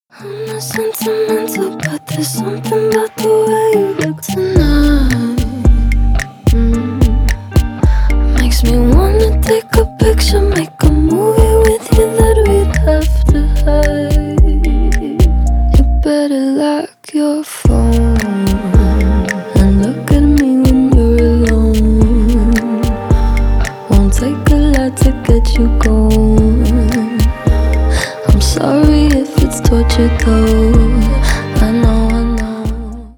бесплатный рингтон в виде самого яркого фрагмента из песни
Рок Металл
спокойные